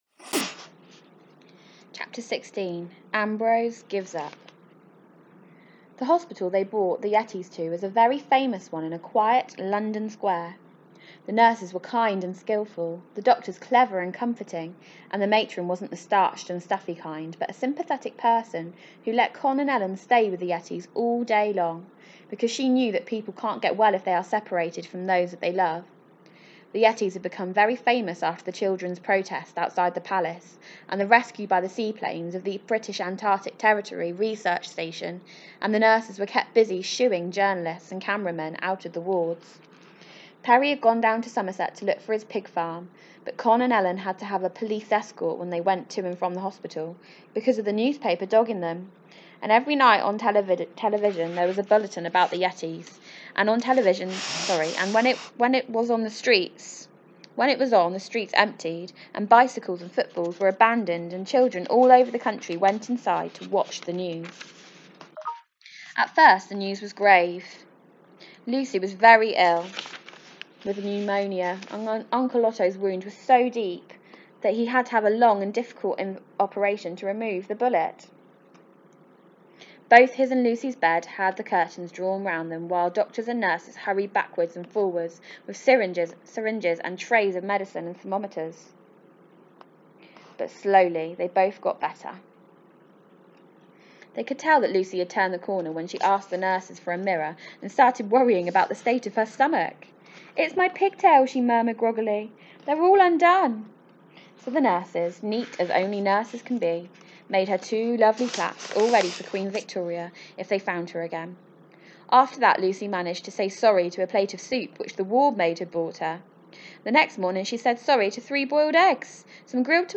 I will read a chapter a day, I am sure the children will love this story as much as I do!